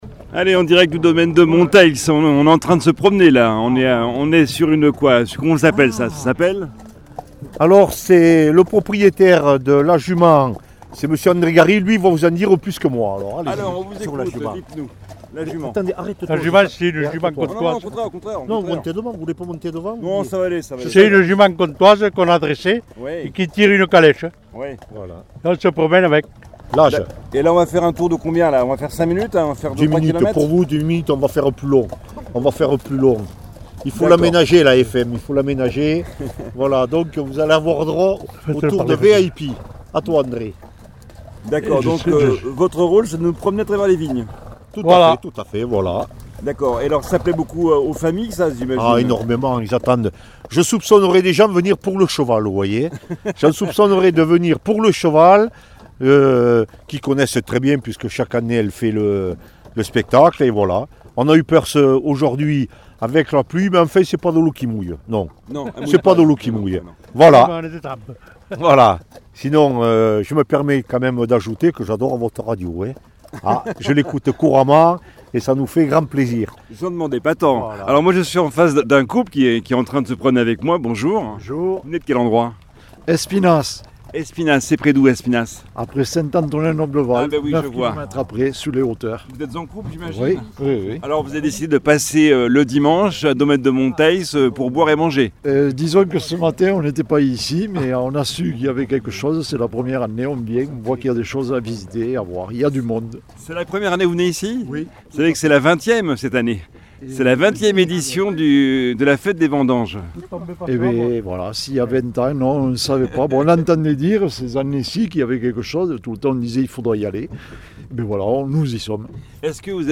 propose une promenade dans le vignoble du Domaine de Montels à l’occasion de la 20e Fête des vendanges...